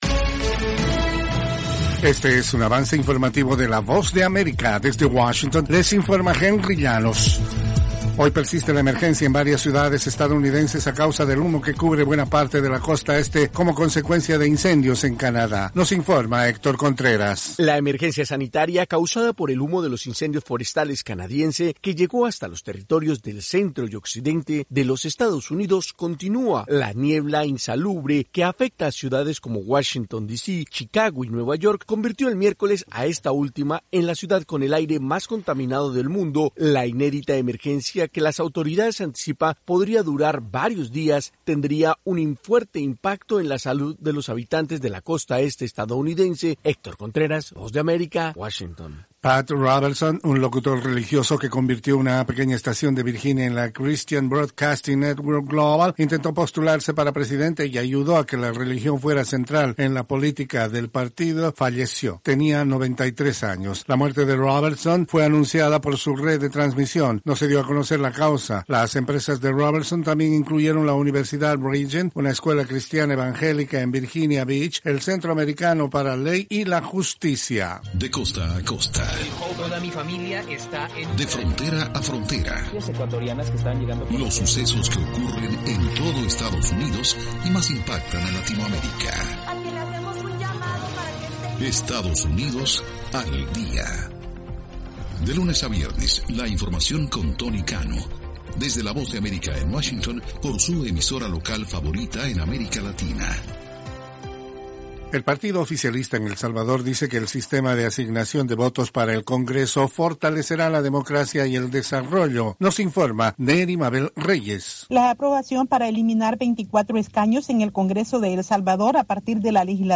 AVANCE INFORMATIVO 1:00 PM
El siguiente es un avance informativo presentado por la Voz de América en Washington